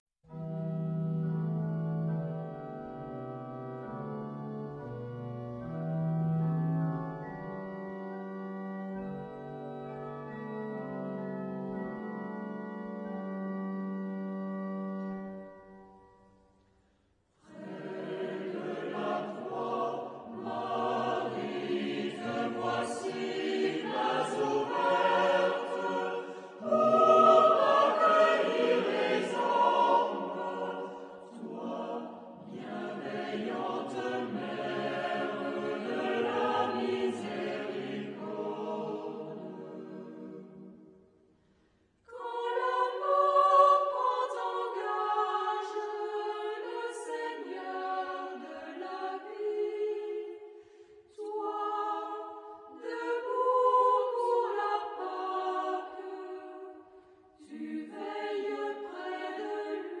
Epoque: 20th century
Genre-Style-Form: Canticle ; Sacred
Type of Choir: SATB  (4 mixed voices )
Tonality: E minor